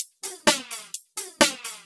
Index of /VEE/VEE2 Loops 128BPM
VEE2 Electro Loop 255.wav